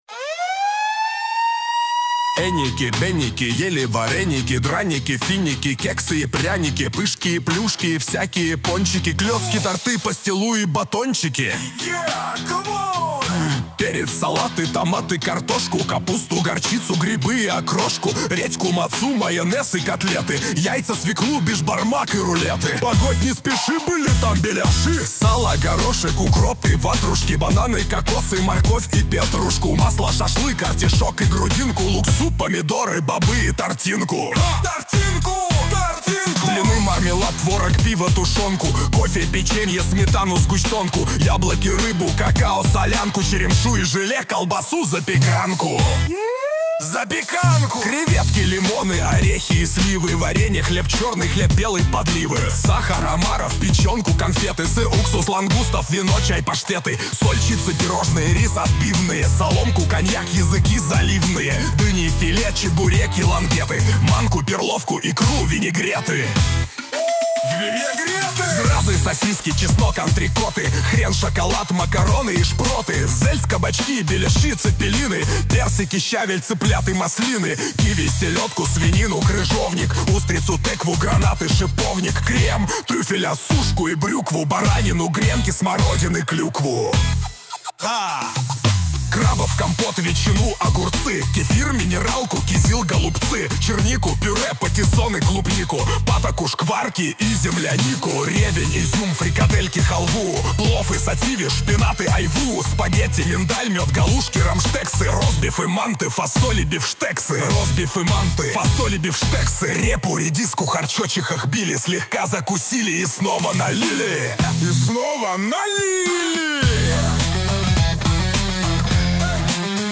mp3,3457k] Рок